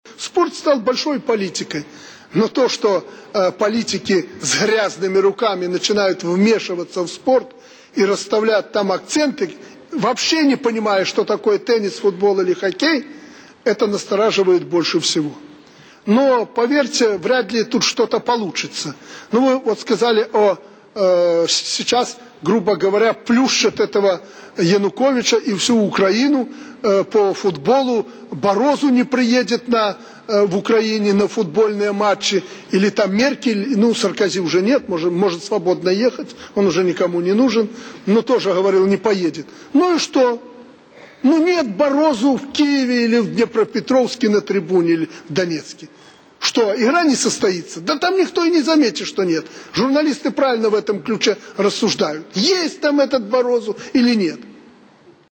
Аляксандар Лукашэнка. Пасланьне да беларускага народу і Нацыянальнага сходу. 8 траўня 2012